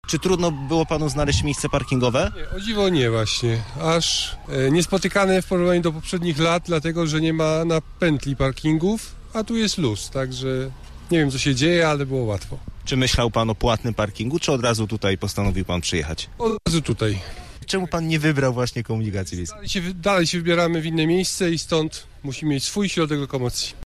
Sytuacja jest dynamiczna i czasem udaje się znaleźć miejsce do zaparkowania przy cmentarzu- mówią mieszkańcy: